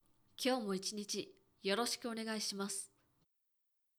ボイス
ダウンロード 中性_「今日も1日よろしくお願いします」
中性女性挨拶